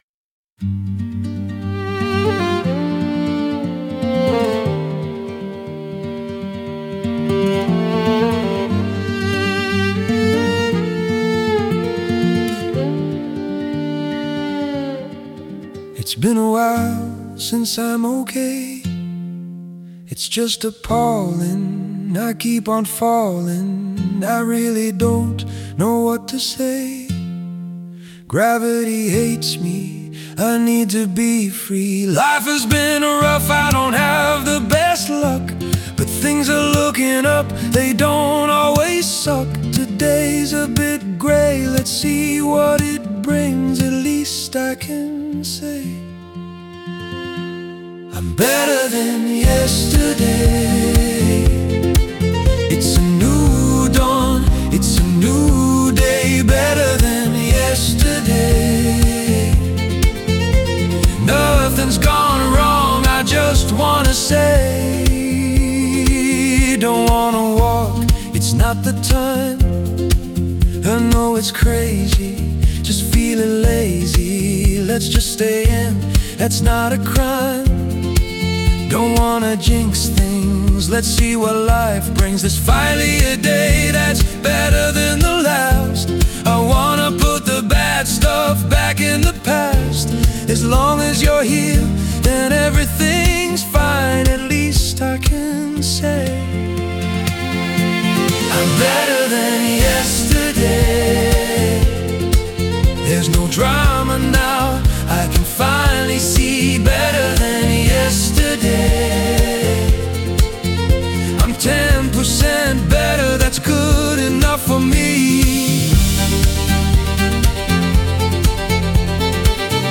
This song absolutely deserved a more stripped-down version.